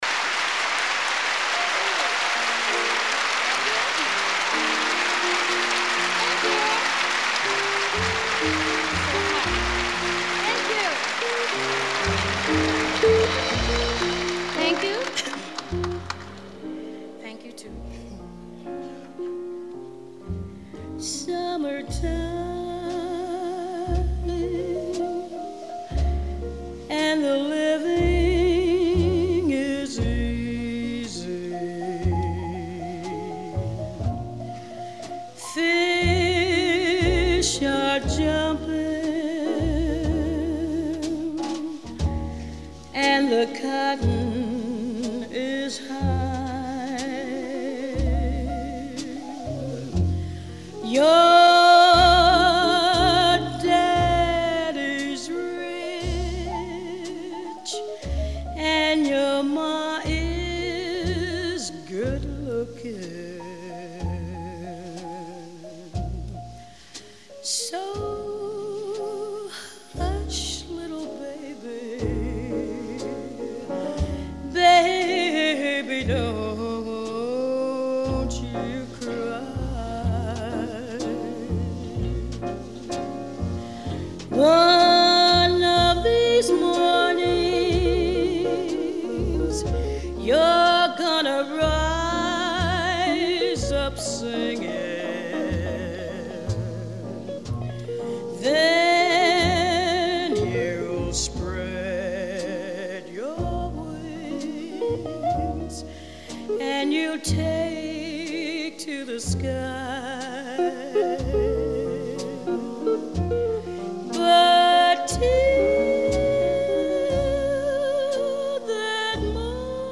limpidez da voz incomparável
em três concertos